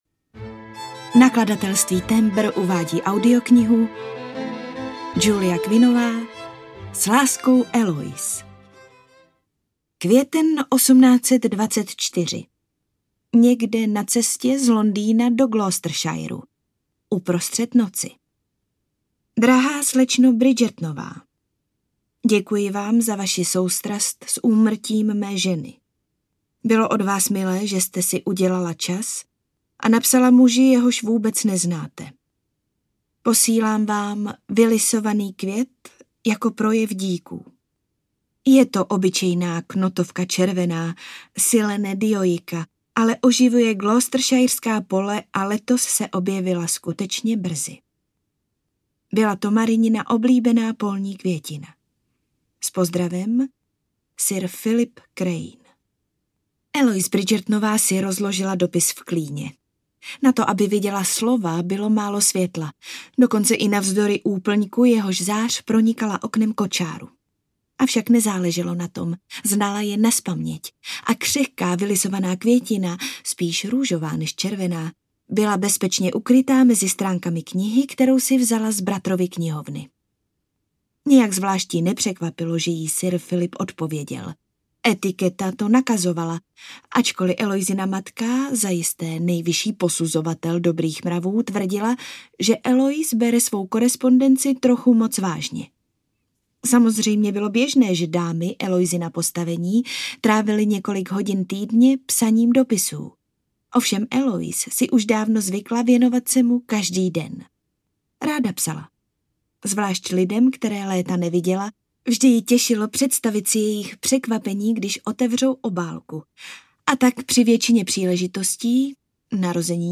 S láskou, Eloise audiokniha
Ukázka z knihy